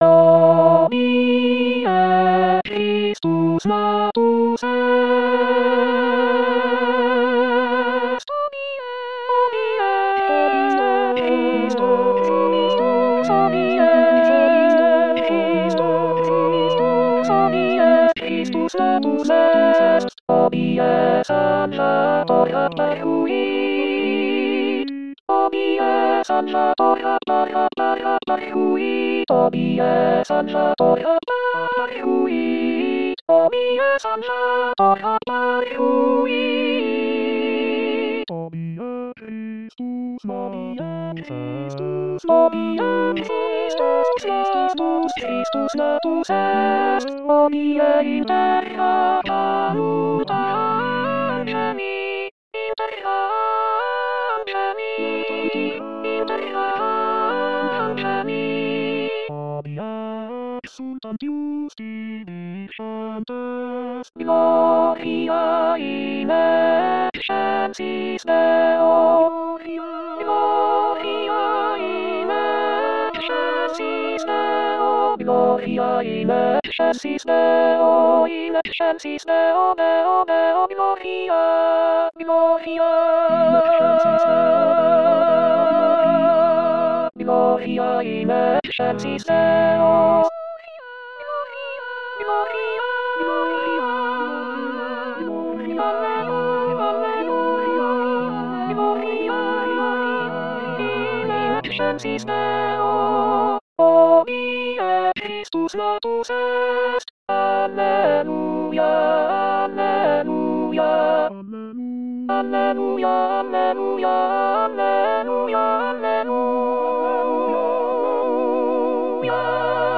Alto Alto 1